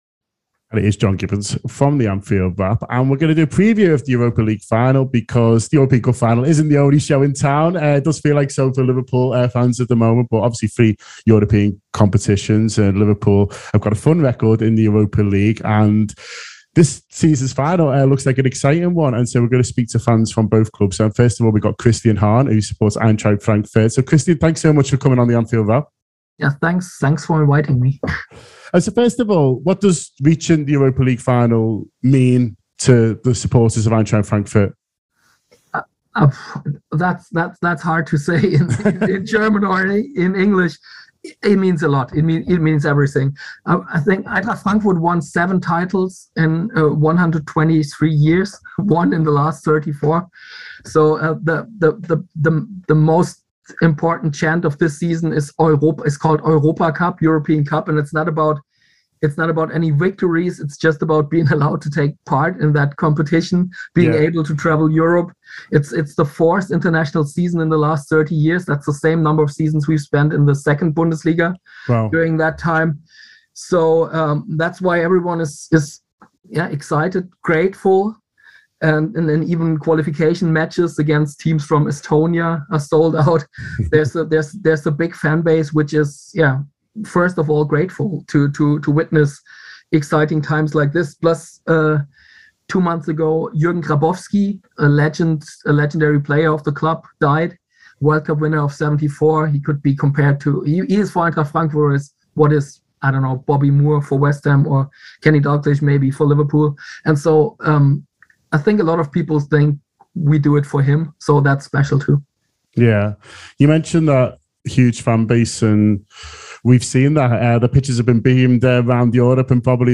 Below is a clip from the show – subscribe for more on Frankfurt v Rangers in the Europa League final…